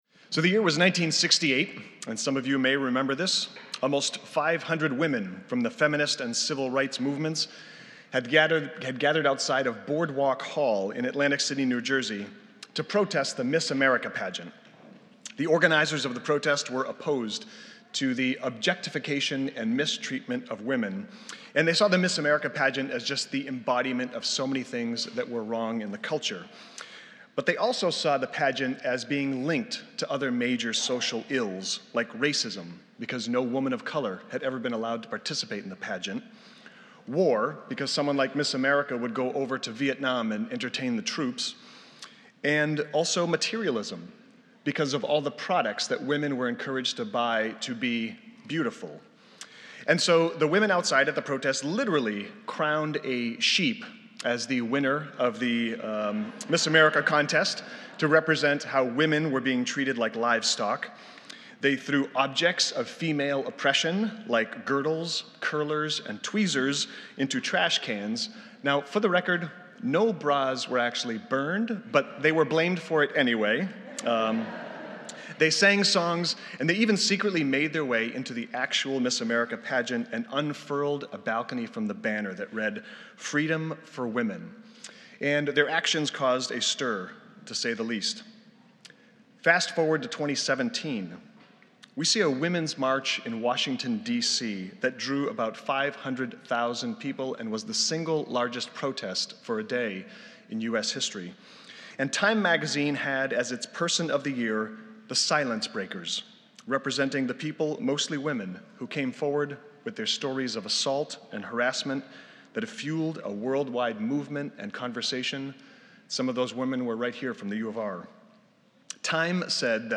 This is a Spiritus Christi Mass in Rochester, NY.